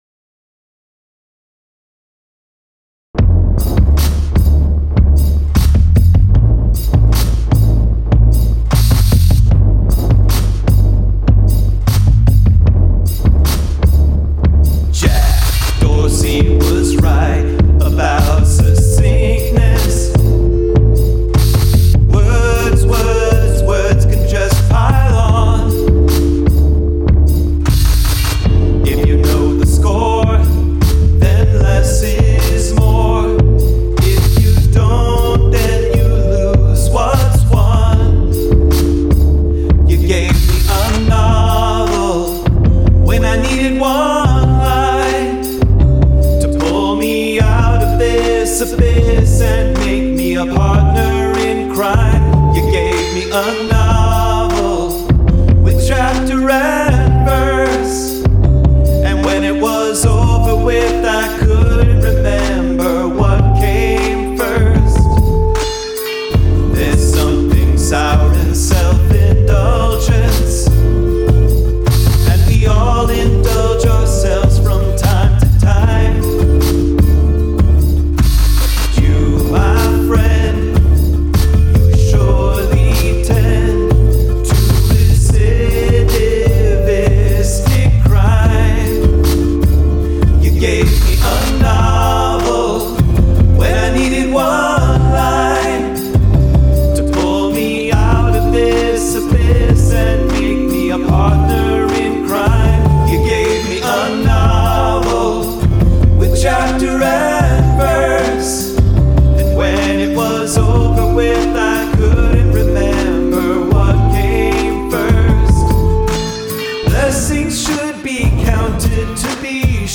acoustic guitar, slide guitar*
Kick- A mostly empty used oil barrel
Snare-Compressed Dry Air gun and a bin of dead battery cells
HH- a small crowbar
other clanks- 2 other crowbars
Bass- Rubber band across plastic Tupperware
Keys- rim of glasses wet
Timing gets a little sloppy around the 2 minute mark.